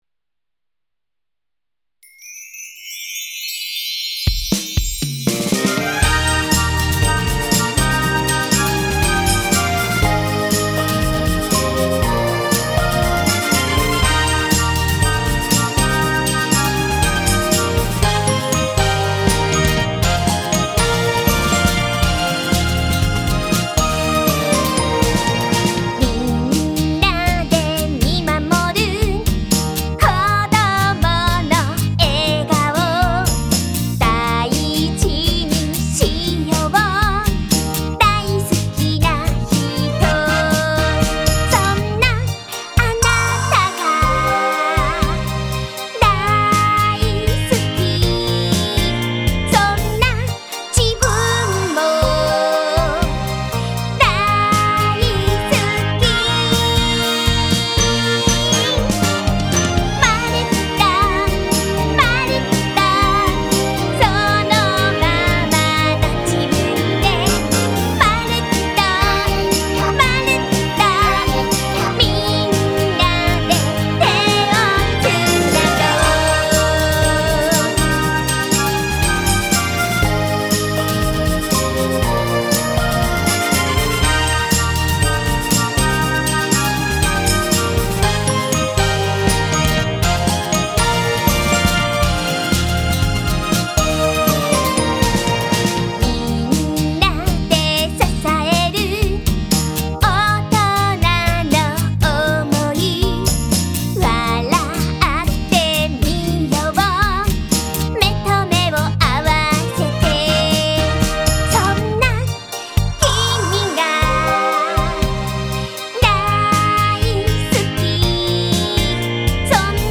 やさしいメロディにのせて、みんなの心にあたたかく届きますように。